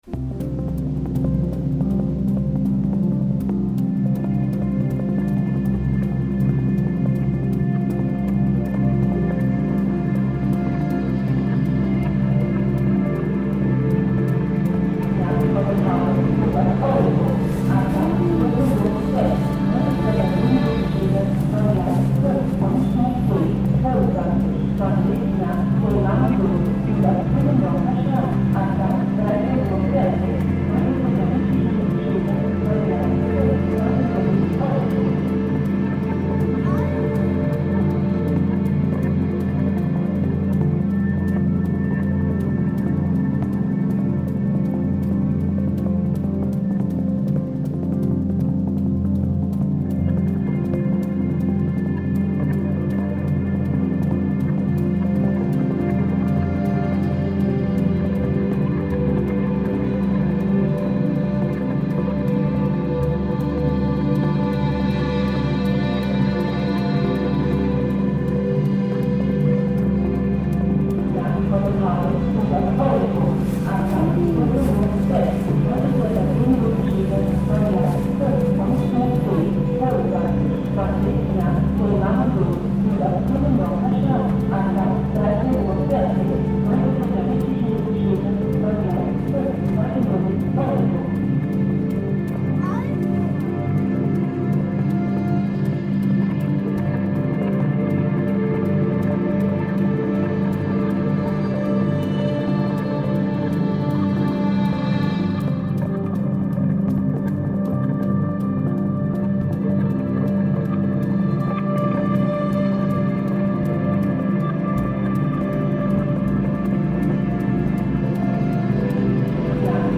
Luebeck station reimagined